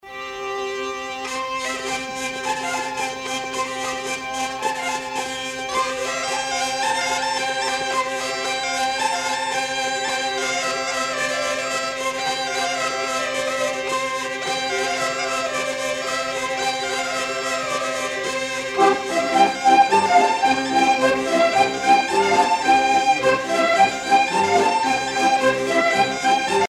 danse : rondeau
circonstance : carnaval, mardi-gras
Pièce musicale éditée